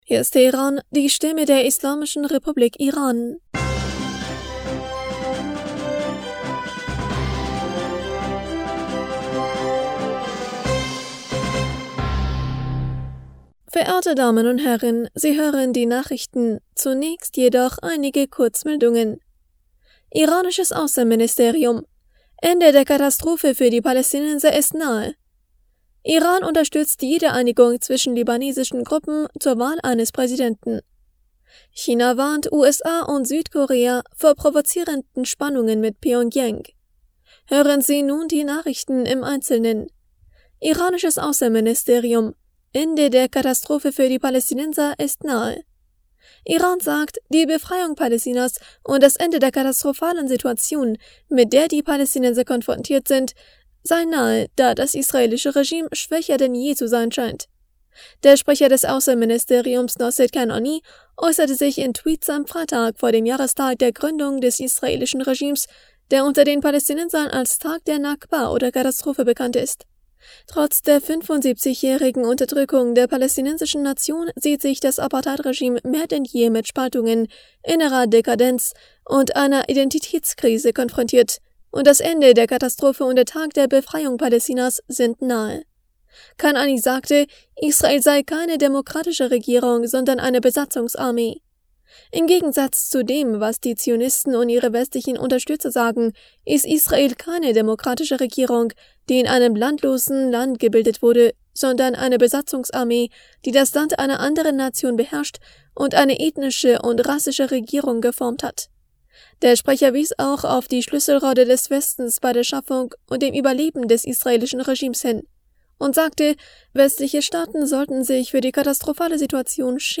Die Nachrichten von Freitag, dem 28. April 2023